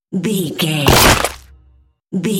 Dramatic hit bloody bone
Sound Effects
heavy
intense
dark
aggressive
hits